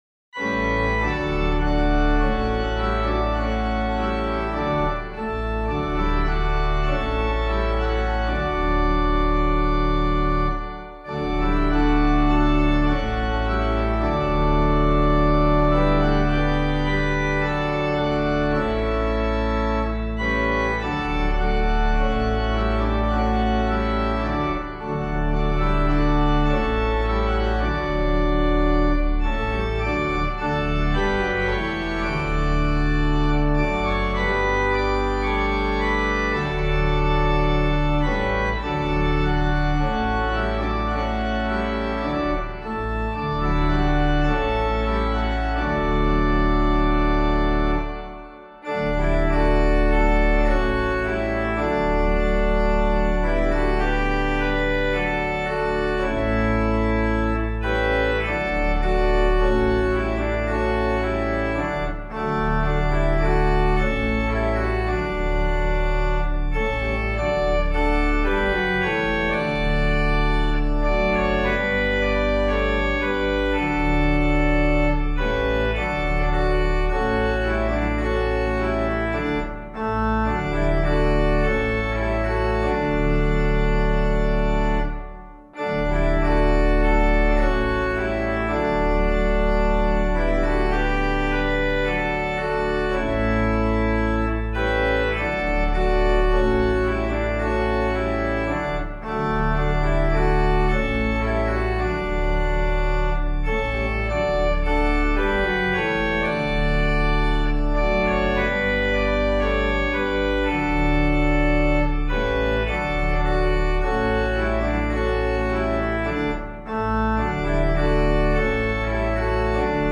Hymn suitable for Catholic liturgy.
English folk tune